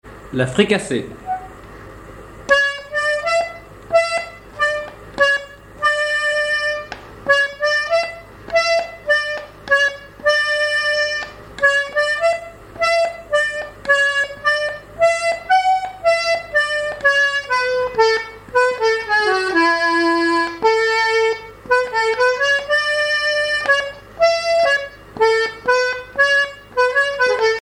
danse : fricassée (danse)
airs de danse à l'accordéon diatonique
Pièce musicale inédite